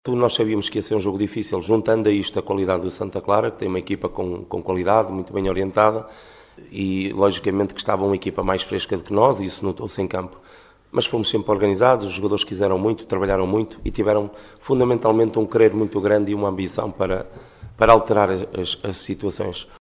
No final do jogo